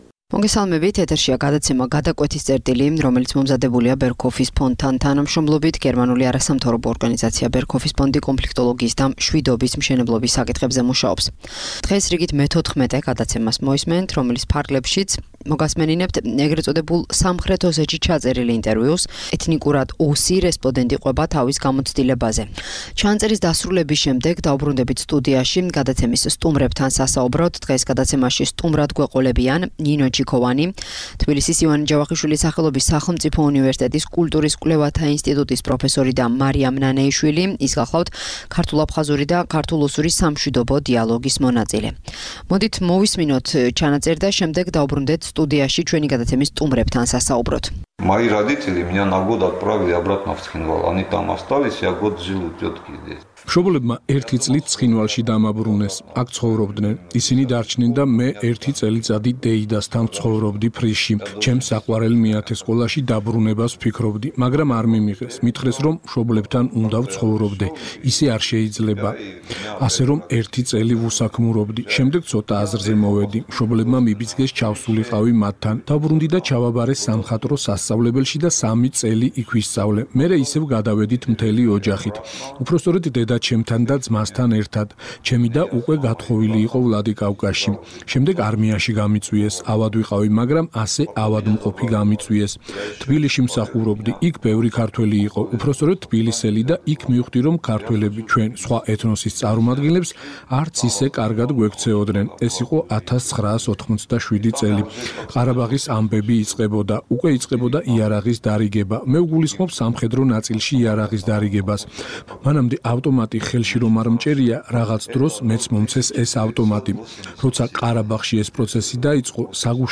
შემოგთავაზებთ ე.წ. სამხრეთ ოსეთში ჩაწერილ ინტერვიუს. რესპონდენტი ჰყვება საზოგადოებებს შორის დაწყებულ გაუცხოებაზე.